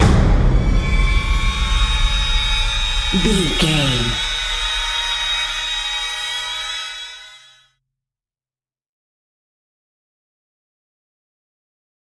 Violin Clusters With Big Hit.
Atonal
tension
ominous
eerie
percussion
strings